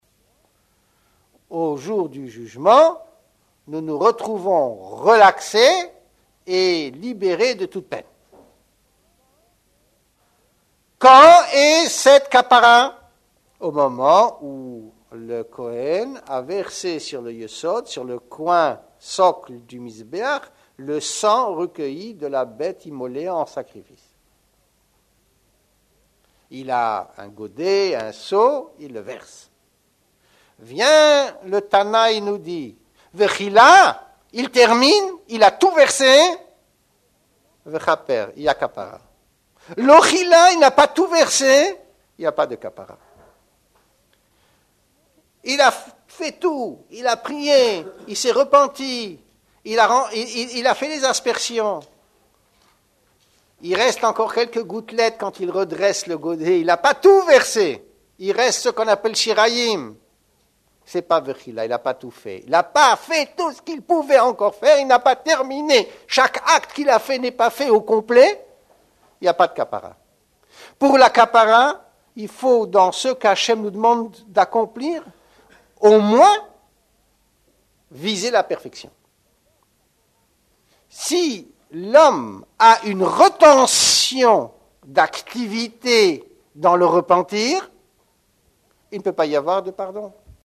Drasha